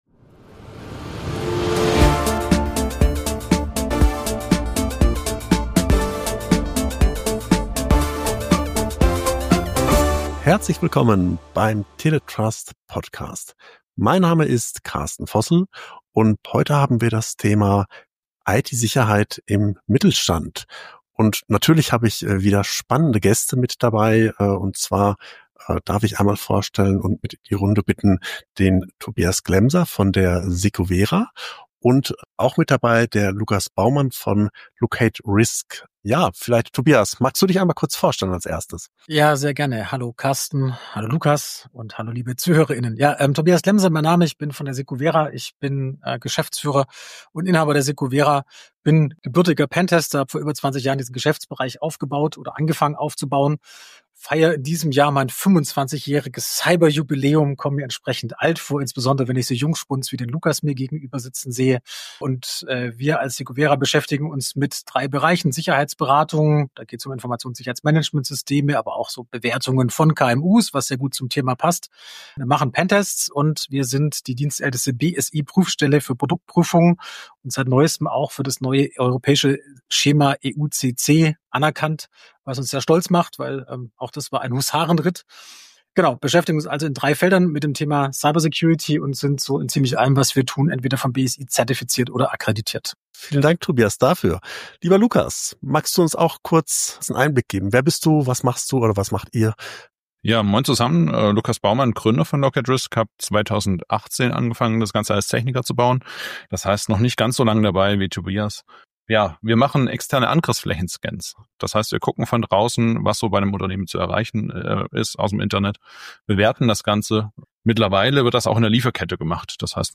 In einer moderierten Gesprächsrunde